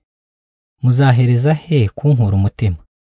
conditioning_audio.wav · DigitalUmuganda/KinyarwandaTTS_female_voice at 5f981ad3182ecf2f2a54def5c225a8d459b662ef